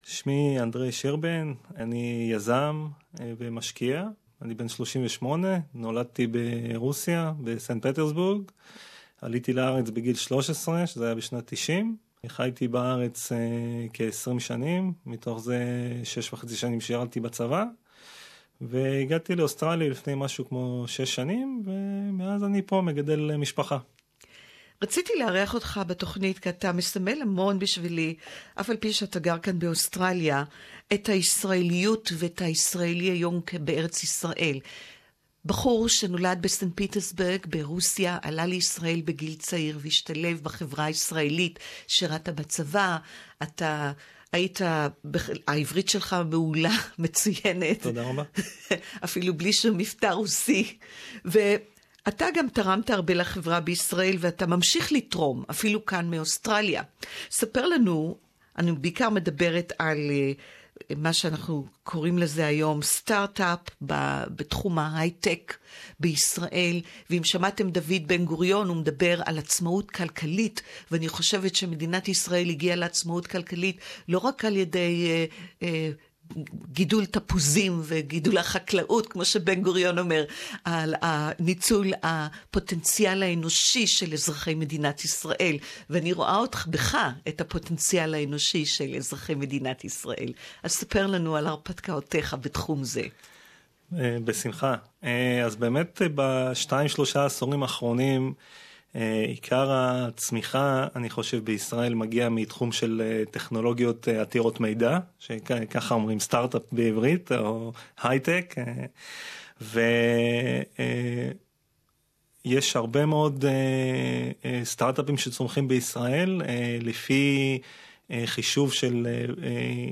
Hebrew Interview